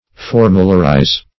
Formularize \For"mu*lar*ize\, v. t. To reduce to a forula; to formulate.